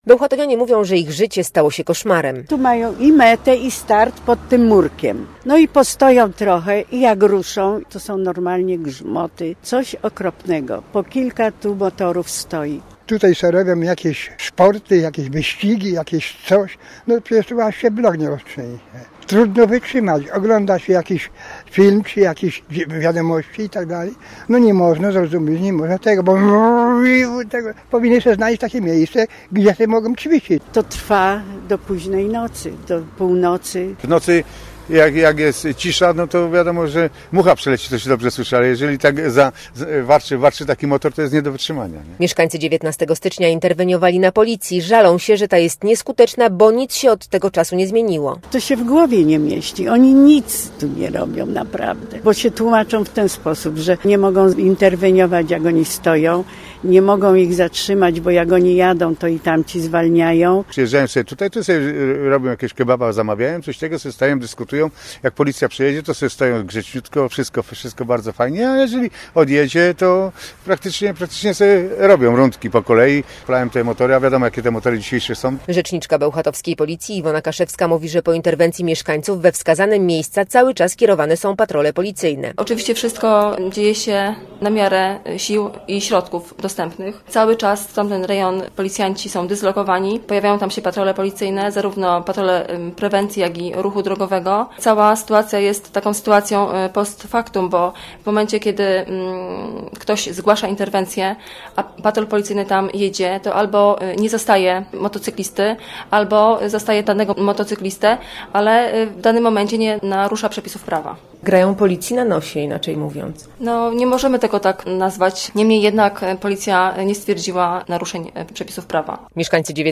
Posłuchaj relacji i dowiedz się więcej: Nazwa Plik Autor Motocyklowy hałas w Bełchatowie audio (m4a) audio (oga) ZDJĘCIA, NAGRANIA WIDEO, WIĘCEJ INFORMACJI Z ŁODZI I REGIONU ZNAJDZIESZ W DZIALE “WIADOMOŚCI”.